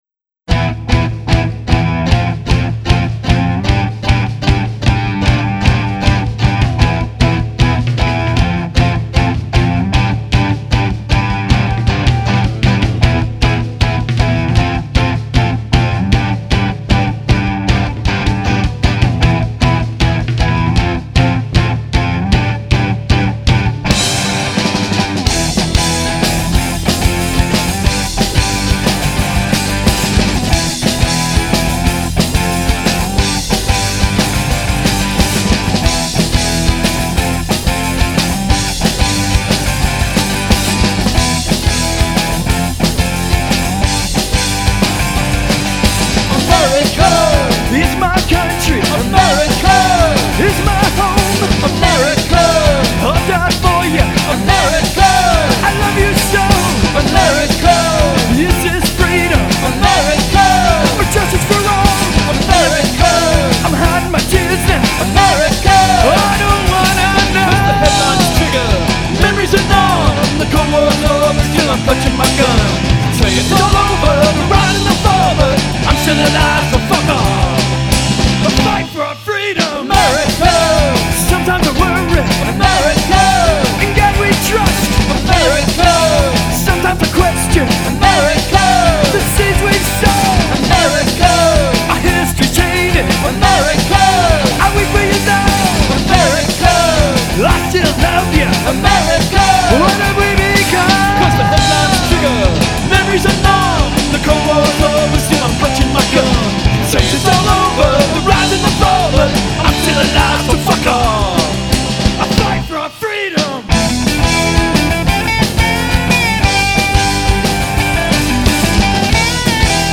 Guitar, Vocals, Bass, Harmonica
Drums
Keyboards